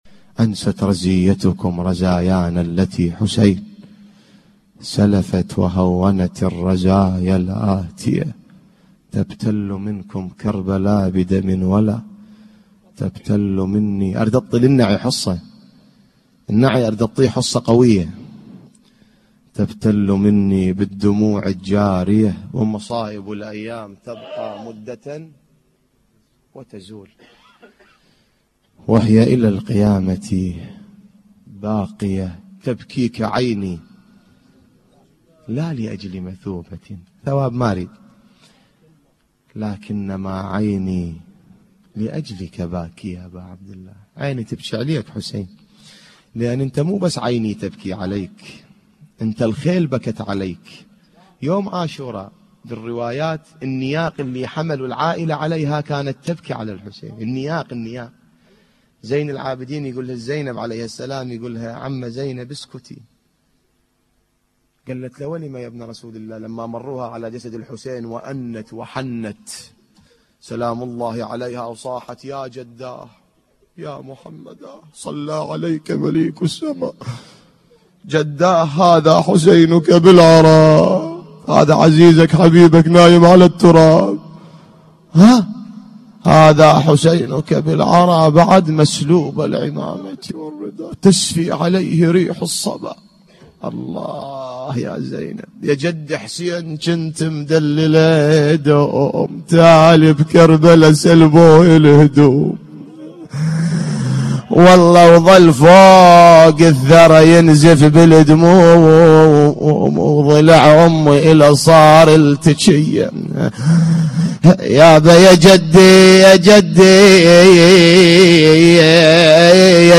للتحميل This entry was posted in نواعي